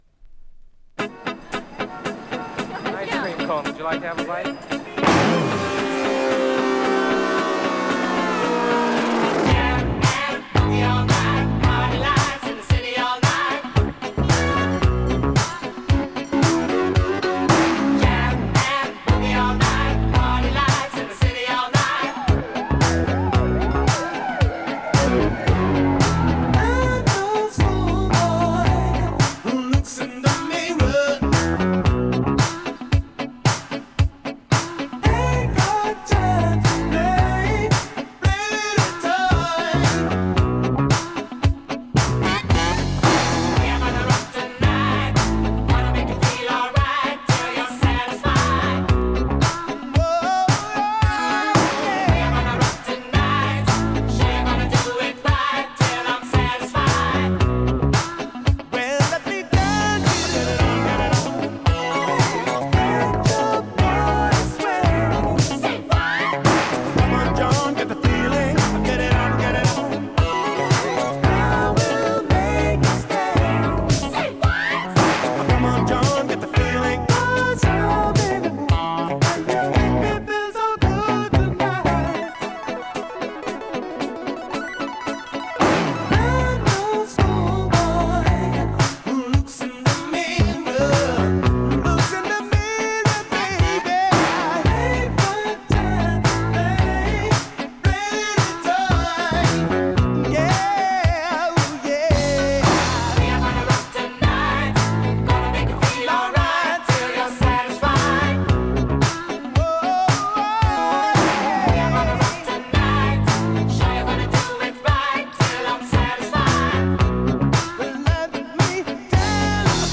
Dutch Electric Boogie!オランダを中心に活躍したソウル・シンガー。
【BOOGIE】
VG/VG 僅かなチリノイズ sleeve